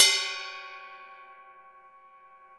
CYM RIDE407R.wav